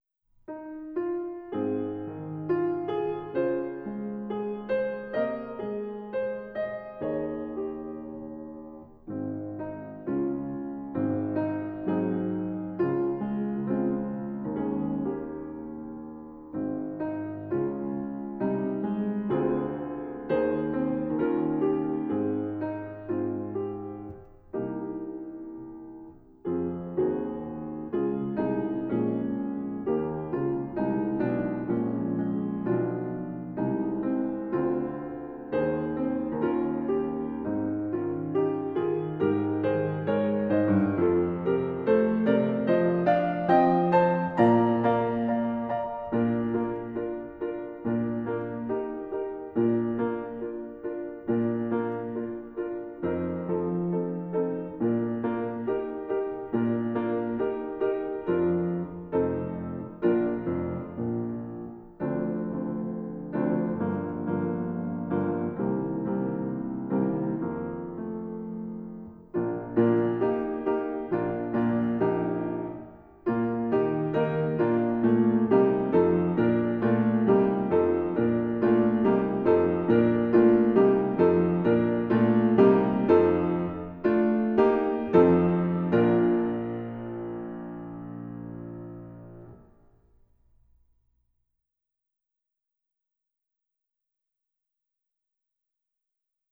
SOLO BRASS
E♭ Accompaniment Track